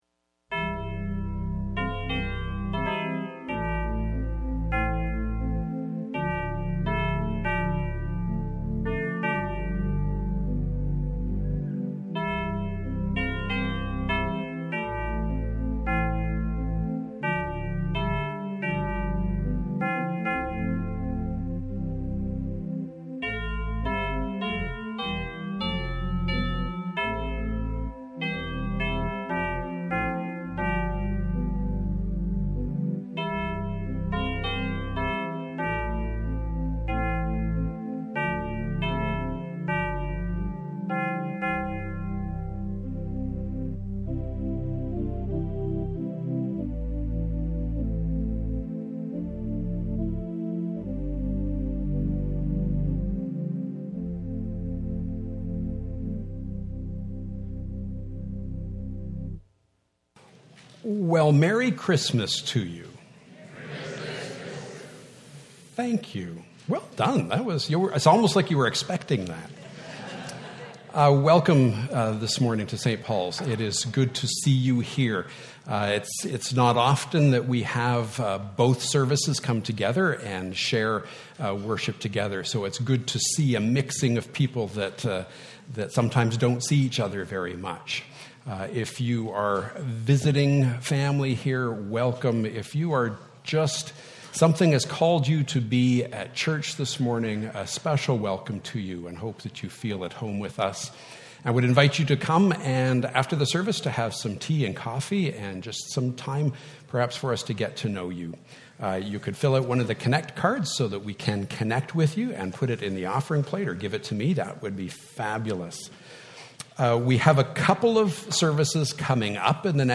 Single Service 2019-12-22 Bible Passage